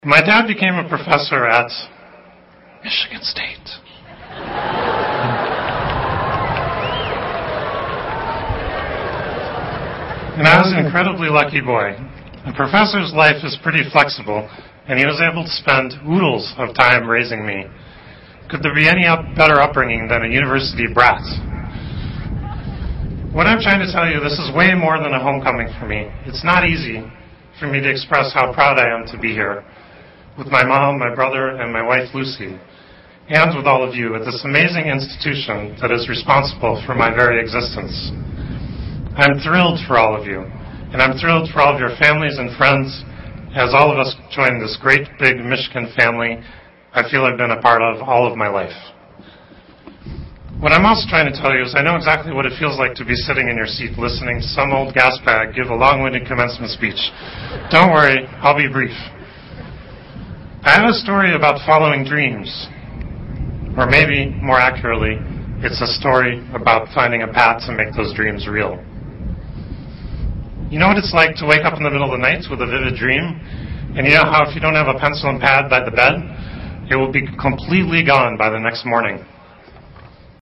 财富精英励志演讲20：拉里·佩奇：梦想的力量(3) 听力文件下载—在线英语听力室